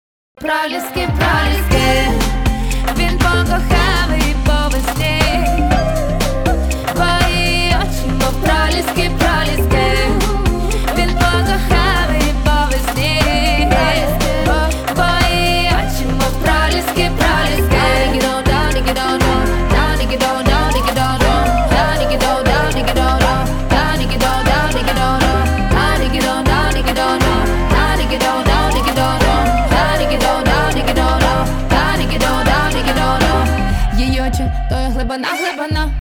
• Качество: 192, Stereo
женский вокал
поп-фолк